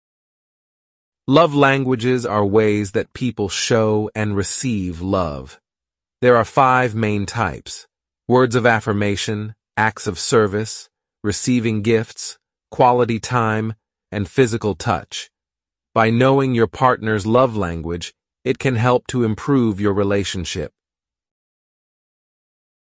Super Realistic AI Voices For Your News Reading